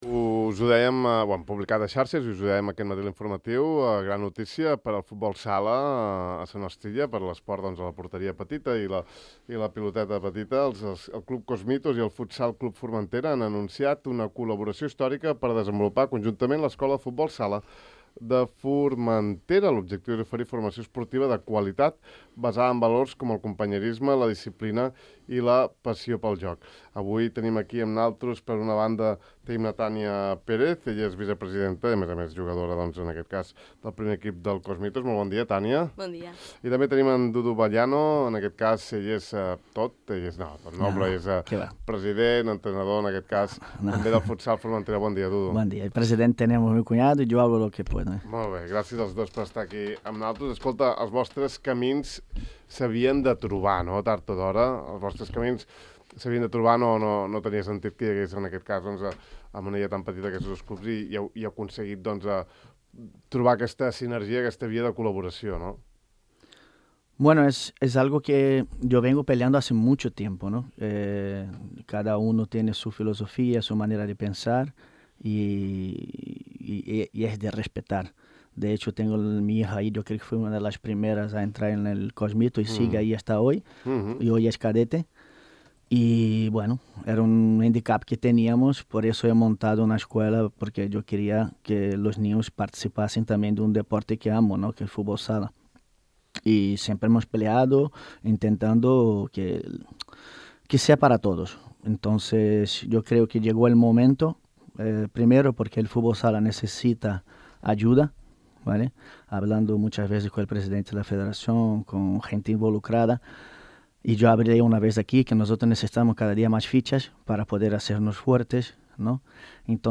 Podeu escoltar l’entrevista sencera al De far a far d’avui aquí: